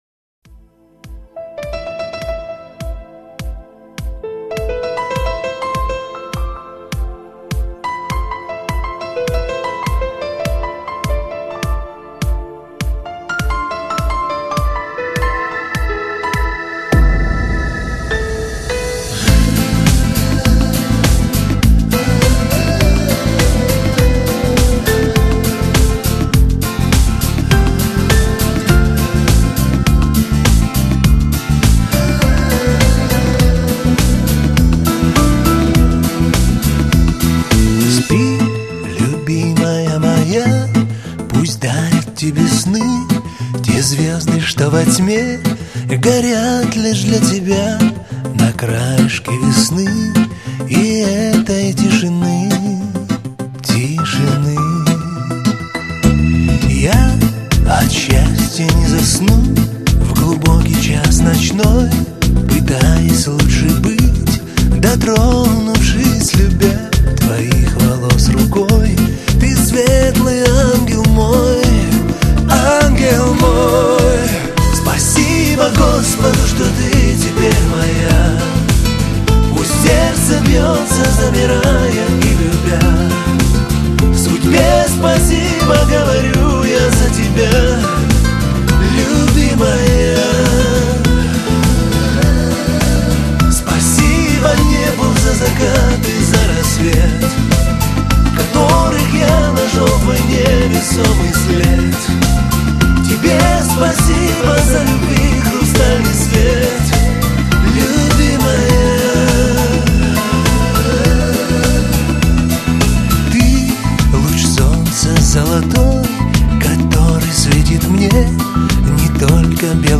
Главная » Файлы » Шансон